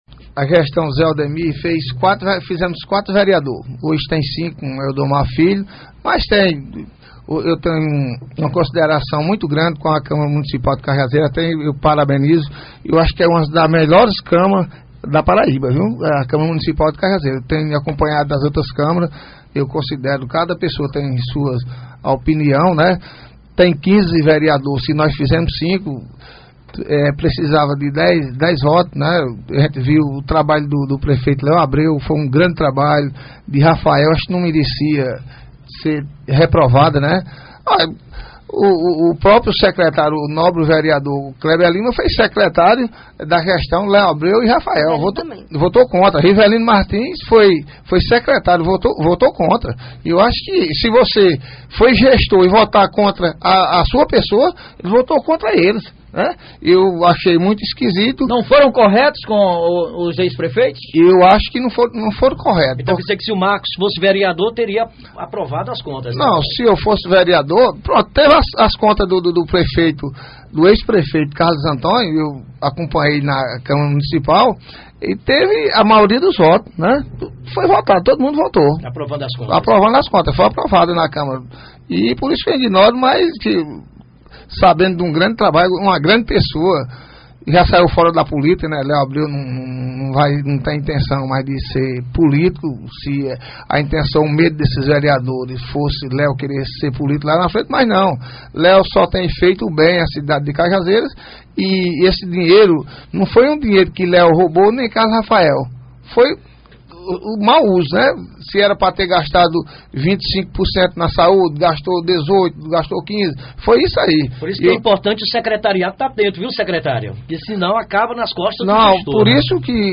O vice – prefeito de Cajazeiras Marcos do Riacho do Meio (PT), falou na tarde de hoje (13) no programa Rádio Vivo pela Alto Piranhas e lamentou a reprovação das contas de Léo Abreu e Carlos Rafael 2011 em sessão realizada na Câmara Municipal de Cajazeiras.